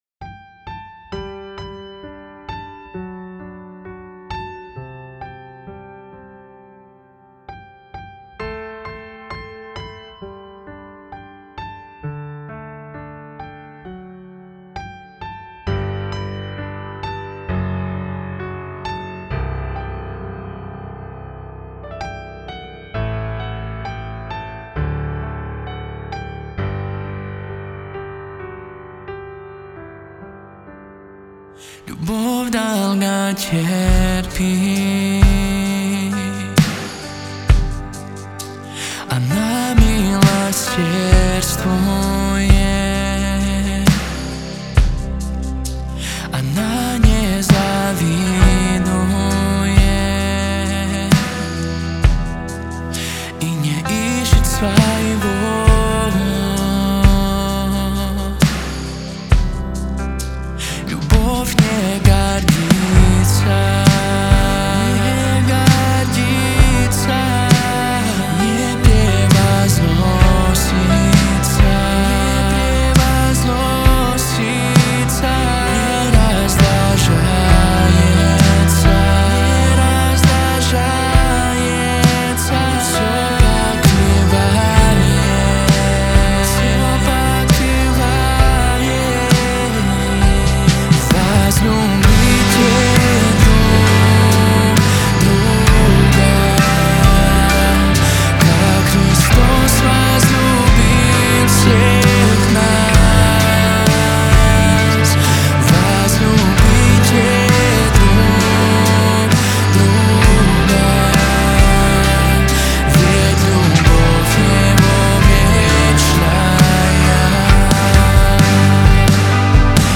песня
70 просмотров 287 прослушиваний 4 скачивания BPM: 67